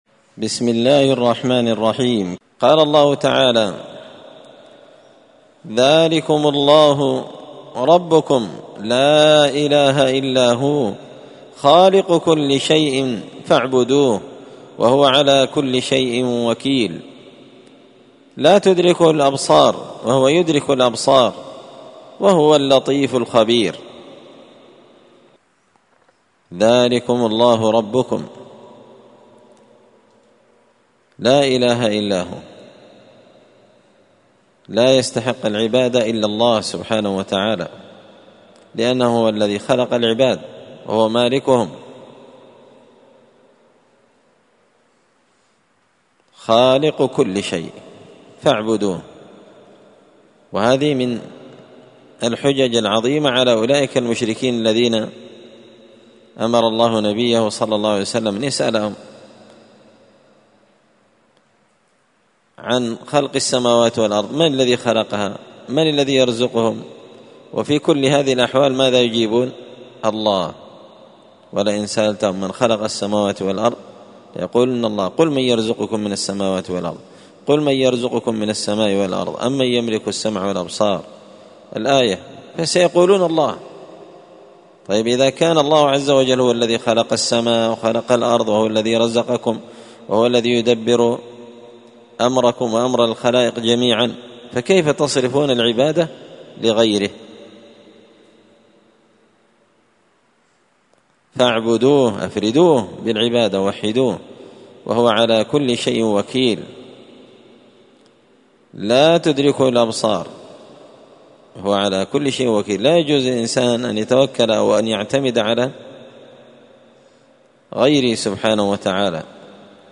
مختصر تفسير الإمام البغوي رحمه الله الدرس 337